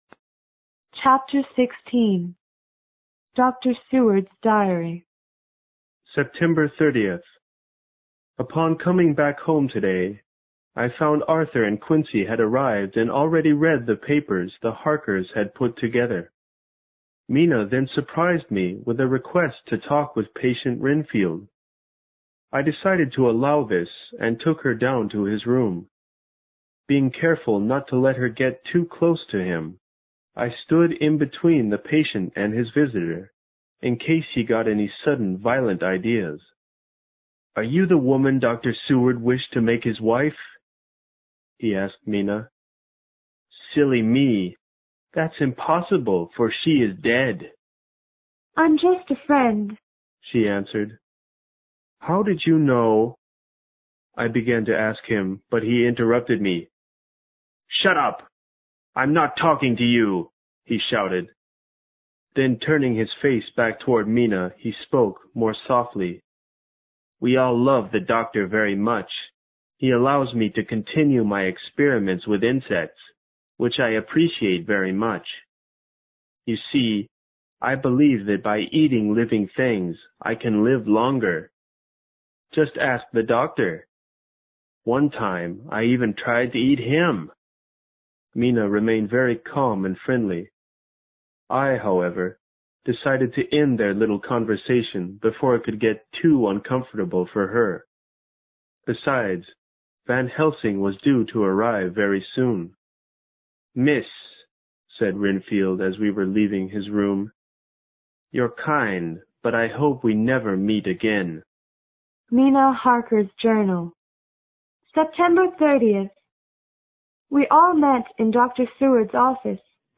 有声名著之吸血鬼 Chapter16 听力文件下载—在线英语听力室